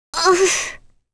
Kirze-Vox_Damage_04.wav